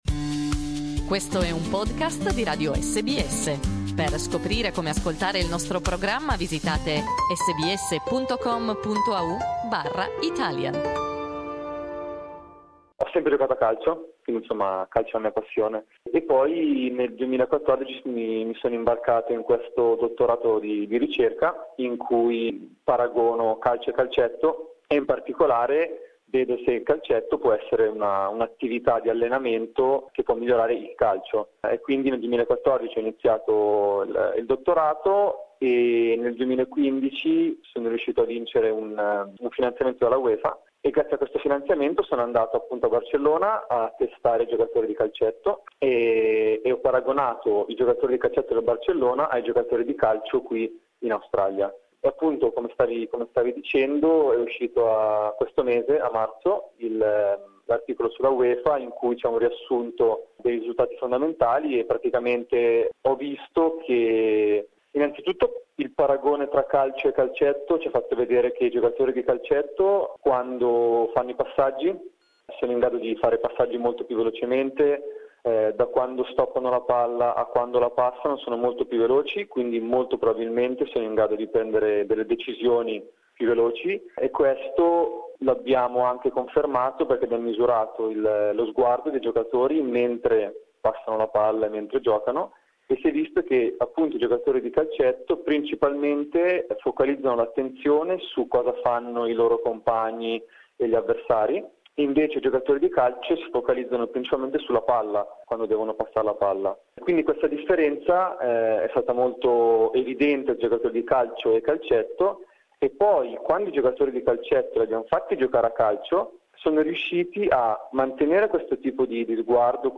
From Futsal to Soccer. Interview